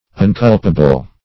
Unculpable \Un*cul"pa*ble\, a. Inculpable; not blameworthy.